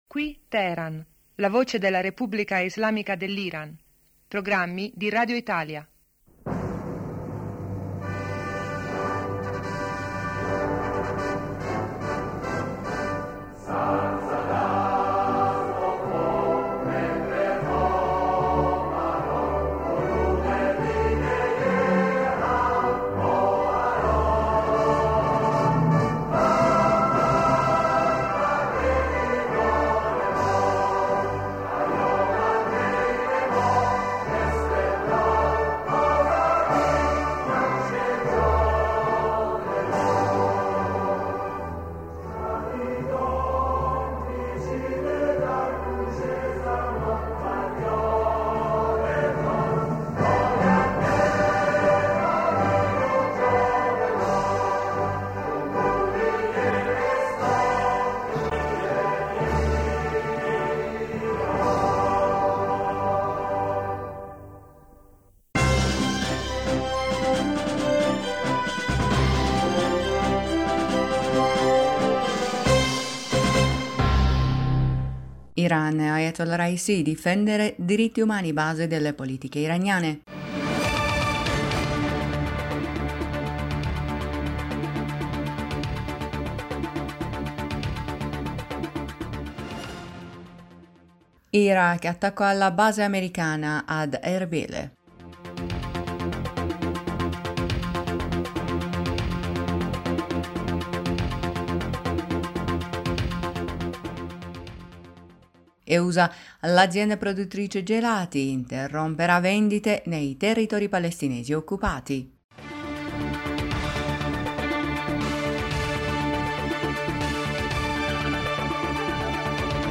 Ecco i titoli più importanti del nostro radiogiornale:1-Iran, Raisi: Difendere diritti umani base delle politiche iraniane, 2-Iraq, attacco alla base ameri...